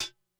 HihatCl.wav